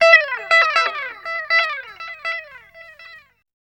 70 GTR 1  -L.wav